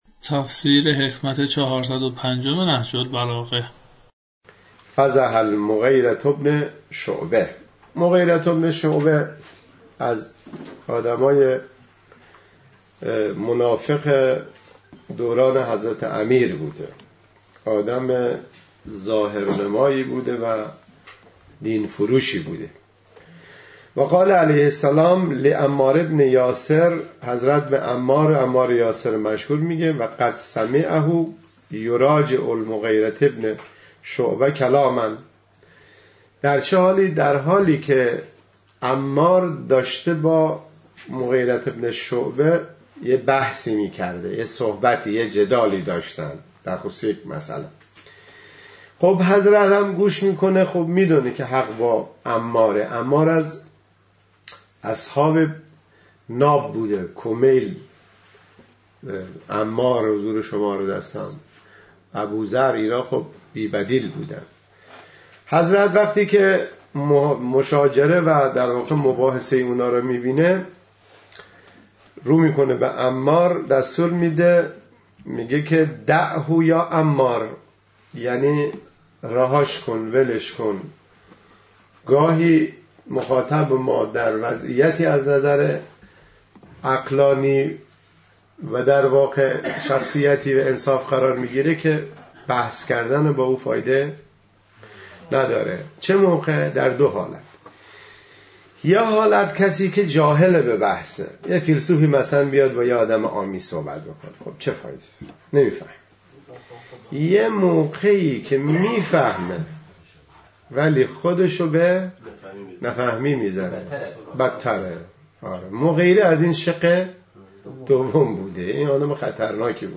تفسیر